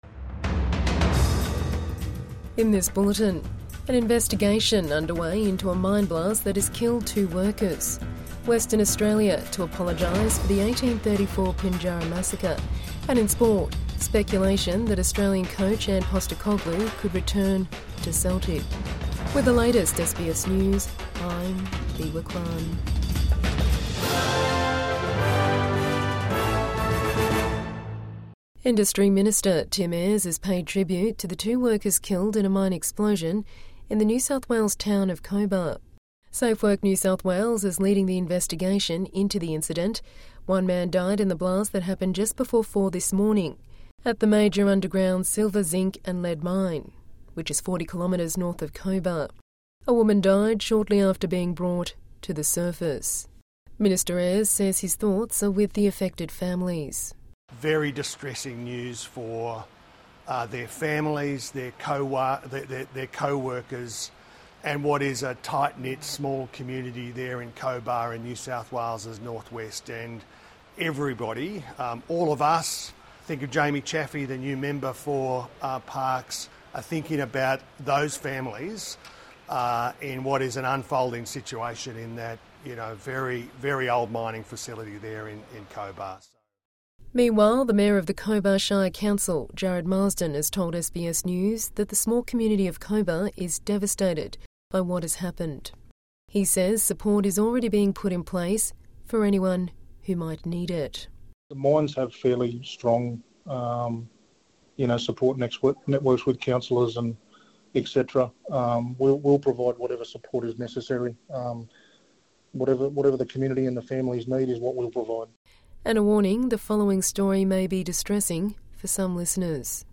Investigation underway into deadly NSW mine explosion | Midday News Bulletin 28 October 2025